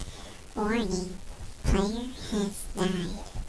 Whenever you frag someone in Renegade, thats the noise that plays.